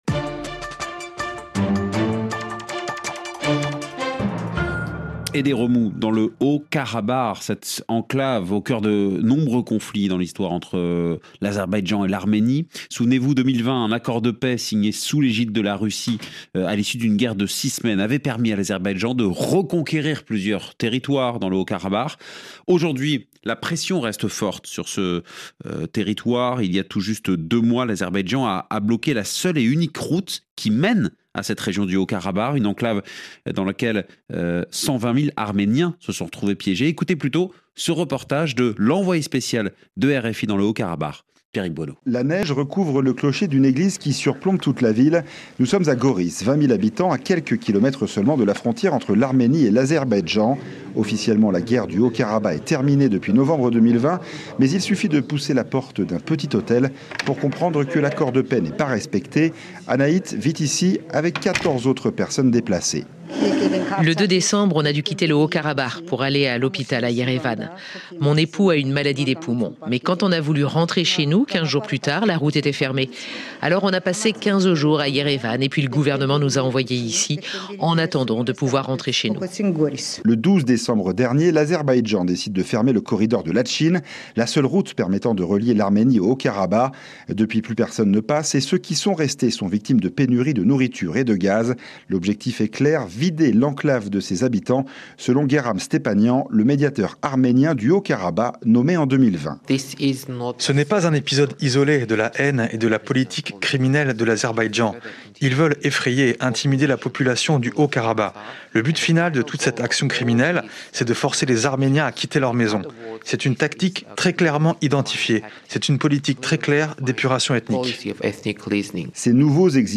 Reportage de Rfi, embarqué (audio) :